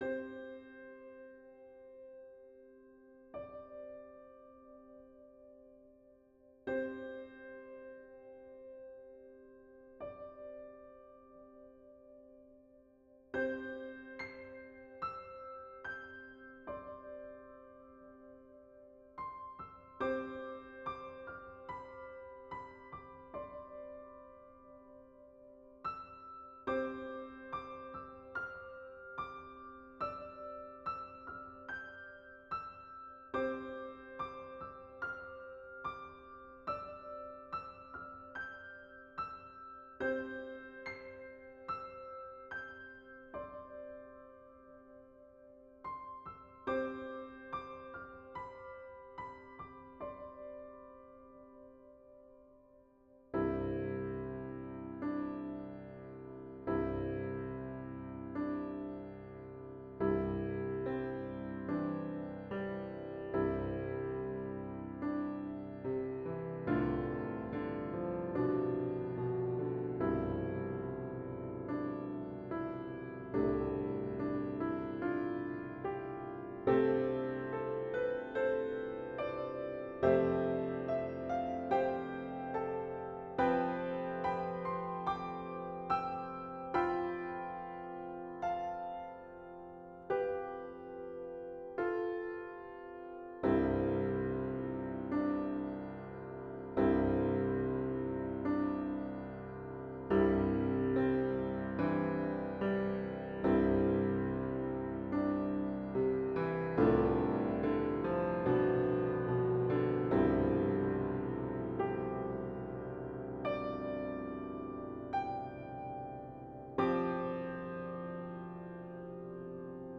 Hymn Christmas arrangement